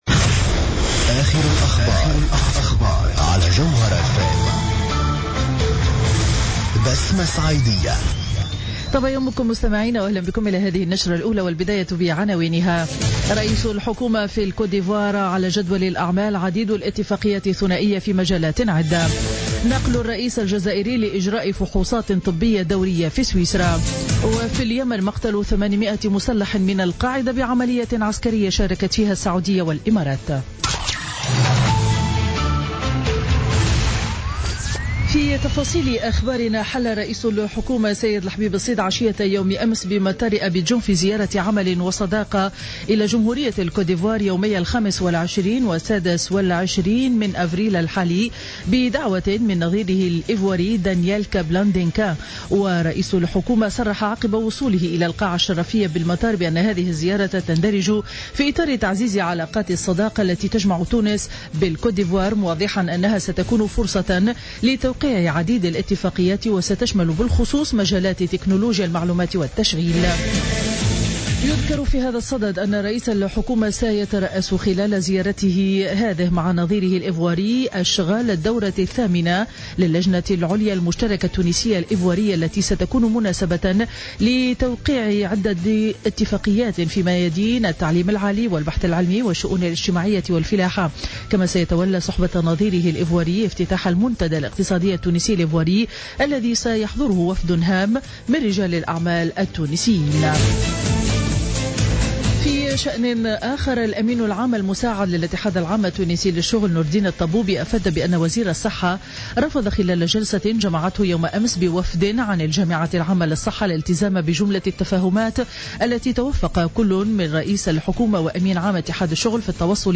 نشرة أخبار السابعة صباحا ليوم الاثنين 25 أفريل 2016